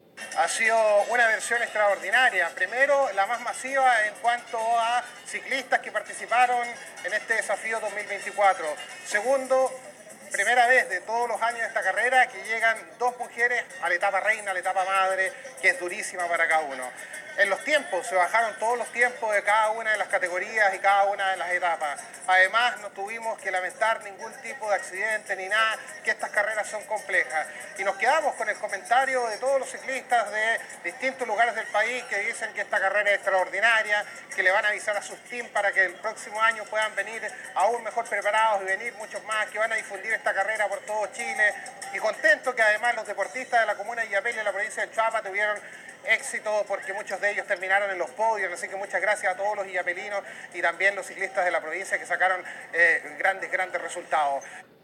alcalde-de-Illapel-cuna-final-Desafio.mp3